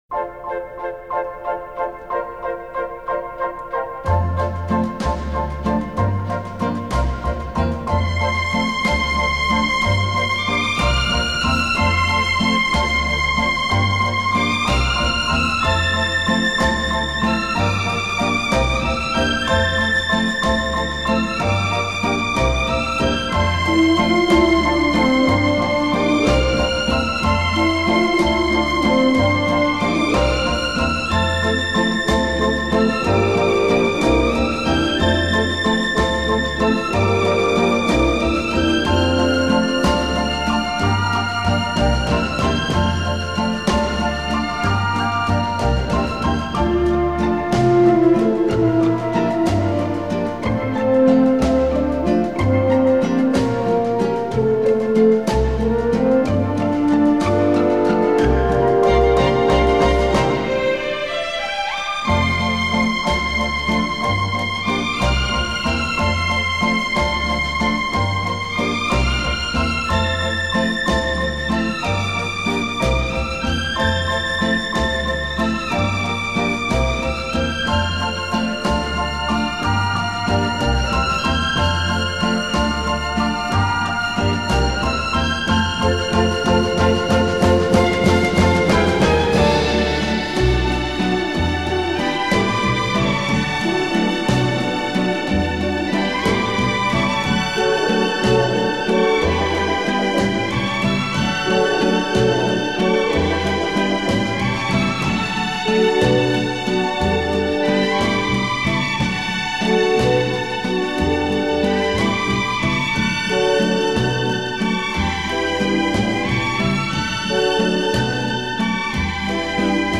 Música Instrumental